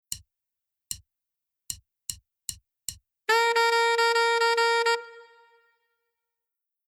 Voicing: Eb Instruments